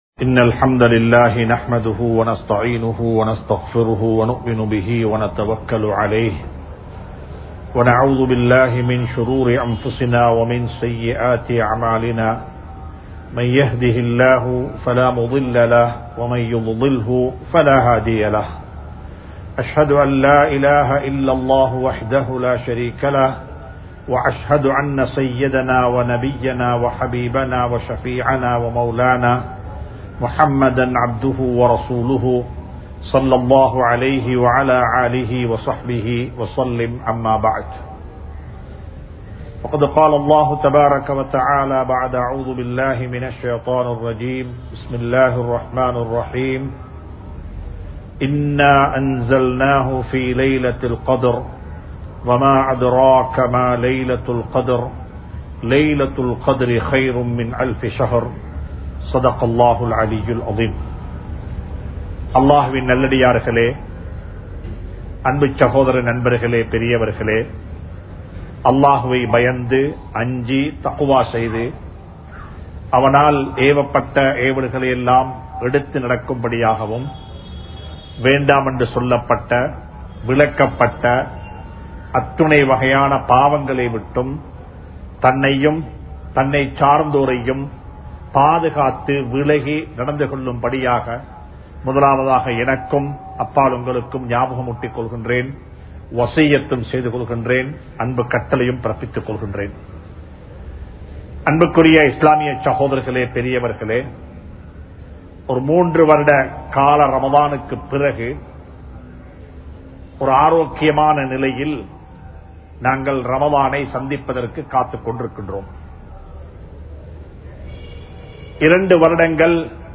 உண்மையான முஸ்லிமாக வாழ்வோம் | Audio Bayans | All Ceylon Muslim Youth Community | Addalaichenai